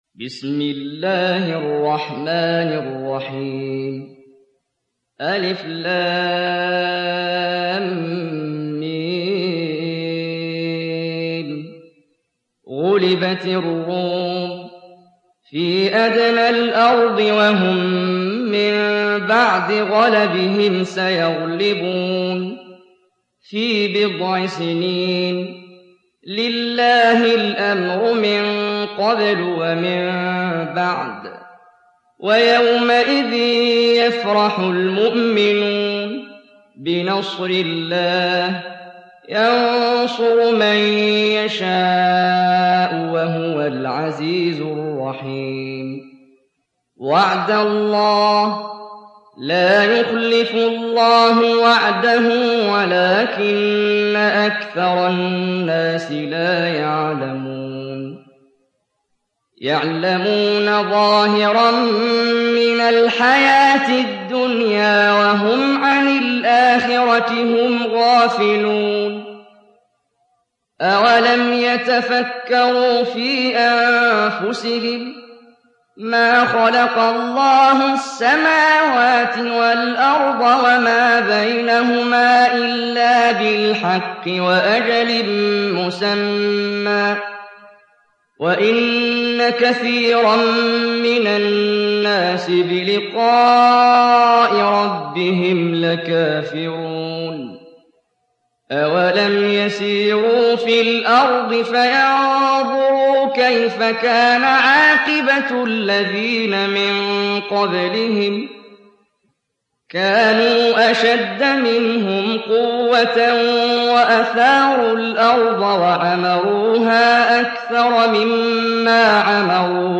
تحميل سورة الروم mp3 بصوت محمد جبريل برواية حفص عن عاصم, تحميل استماع القرآن الكريم على الجوال mp3 كاملا بروابط مباشرة وسريعة